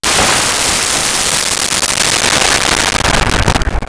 Rocket Launcher Fire
Rocket Launcher Fire.wav